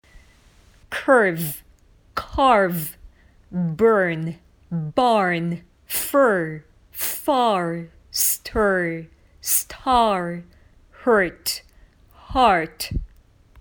（左側が強あいまい母音です）
curve [kˈɚːv]（曲線）  –  carve [kάɚv]（切り分ける）
burn [bˈɚːn]（燃える）  –  barn [bάɚn]（納屋）
左、右と連続で言ってみましたので、聴き比べてみてくださいね。
⇒ 発音見本は
strong schwa2.mp3